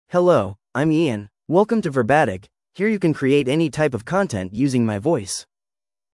MaleEnglish (United States)
IanMale English AI voice
Ian is a male AI voice for English (United States).
Voice sample
Listen to Ian's male English voice.
Ian delivers clear pronunciation with authentic United States English intonation, making your content sound professionally produced.